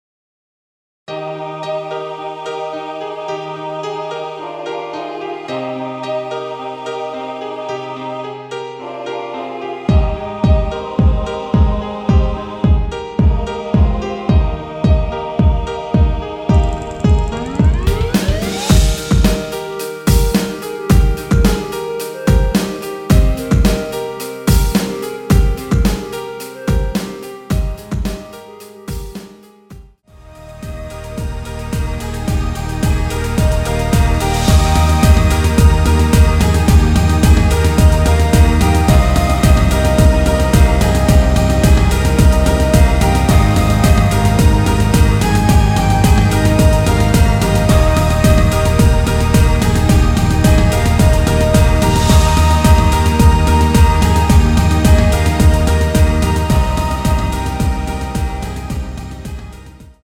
원키에서(-2)내린 멜로디 포함된 MR입니다.
Eb
멜로디 MR이라고 합니다.
앞부분30초, 뒷부분30초씩 편집해서 올려 드리고 있습니다.
중간에 음이 끈어지고 다시 나오는 이유는